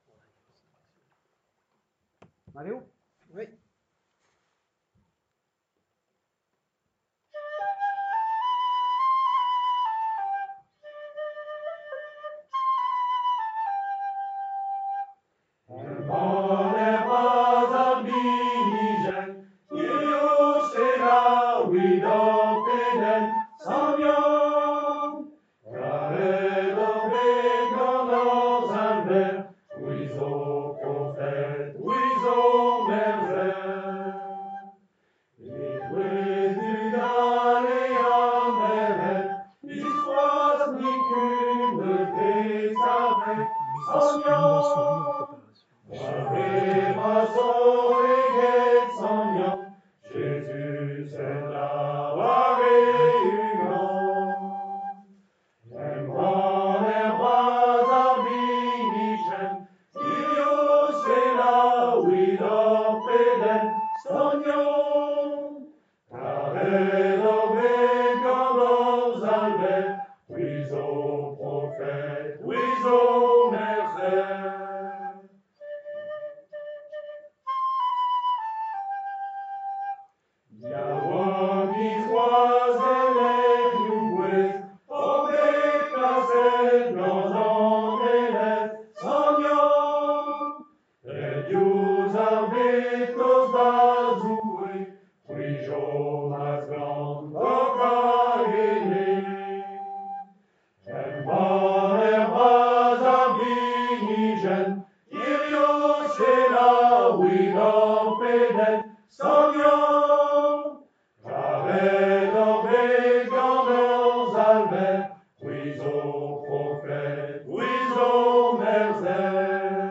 Le pardon en musique